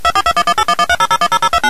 6. Пультовая станция (в нашем случае её эмулирует Мега) даёт 🤝handshake (приветствие) - два импульса 1400 Гц и 2300 Гц, каждый длительностью 100 мс с паузой 100 мс;
сообщение Ademco ConactID - 16 тонов ACCT MT QXYZ GG CCC S: